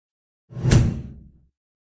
Stamp Impact Sound Effect Free Download
Stamp Impact